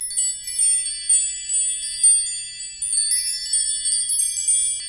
Index of /90_sSampleCDs/Roland - Rhythm Section/PRC_Asian 2/PRC_Windchimes
PRC CHIME07R.wav